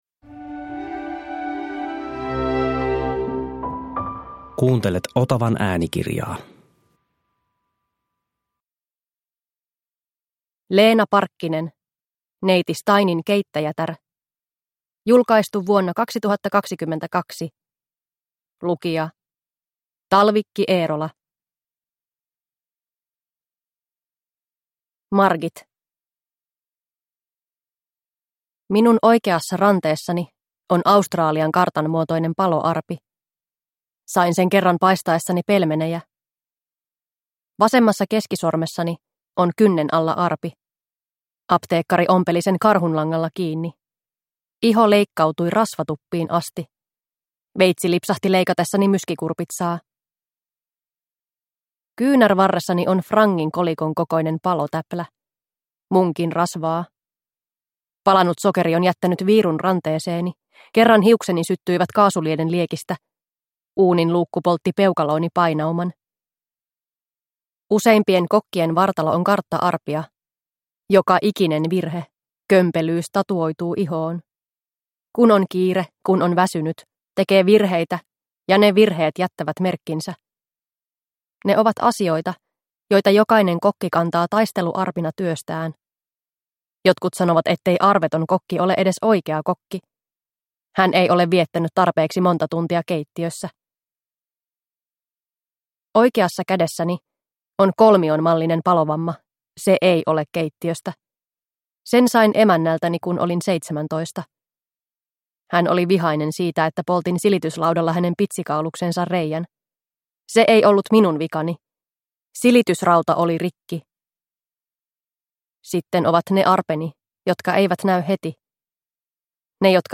Neiti Steinin keittäjätär – Ljudbok – Laddas ner